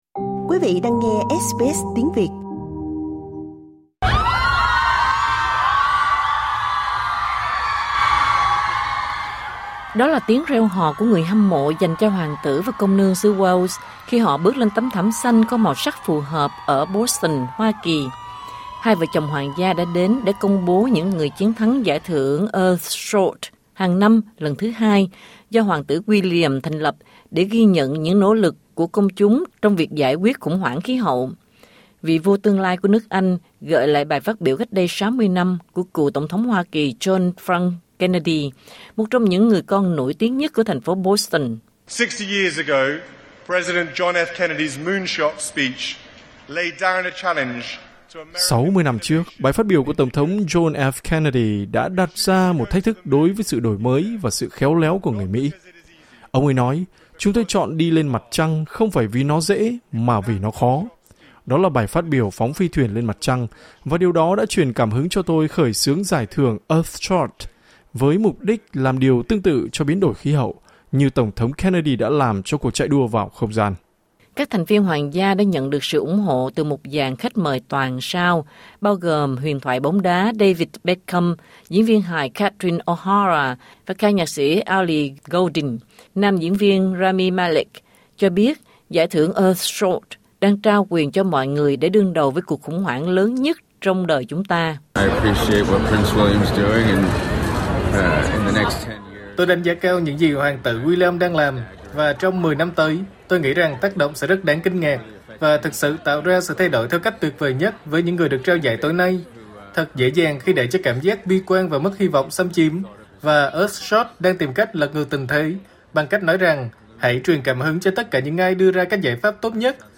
Đó là tiếng reo hò của người hâm mộ dành cho Hoàng tử và Công nương xứ Wales khi họ bước trên tấm thảm xanh có màu sắc phù hợp ở Boston Hoa Kỳ.